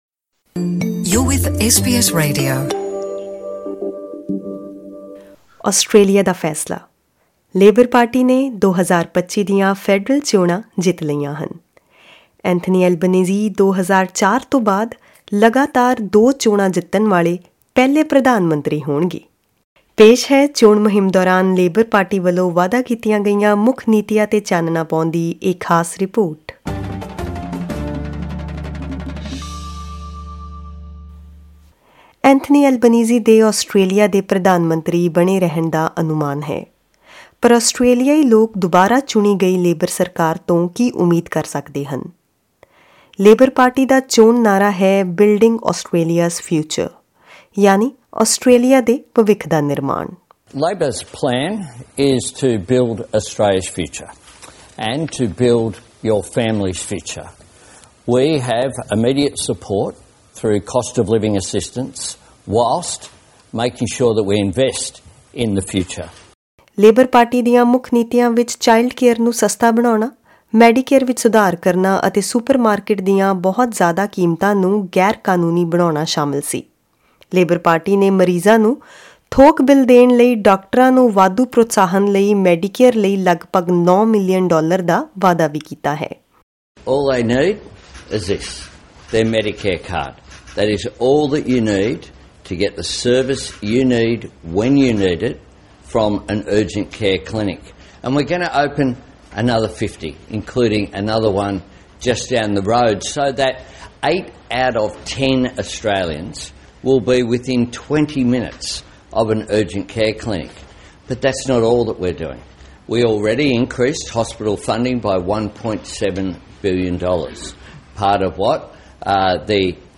ਆਸਟ੍ਰੇਲੀਆਈ ਲੋਕ ਦੁਬਾਰਾ ਚੁਣੀ ਗਈ ਲੇਬਰ ਸਰਕਾਰ ਤੋਂ ਕੀ ਉਮੀਦ ਕਰ ਸਕਦੇ ਹਨ? ਪੇਸ਼ ਹੈ ਚੋਣ ਮੁਹਿੰਮ ਦੌਰਾਨ ਲੇਬਰ ਵੱਲੋਂ ਵਾਅਦਾ ਕੀਤੀਆਂ ਗਈਆਂ ਮੁੱਖ ਨੀਤੀਆਂ ਤੇ ਚਾਨਣਾ ਪਾਉਂਦੀ ਇਹ ਖਾਸ ਰਿਪੋਰਟ।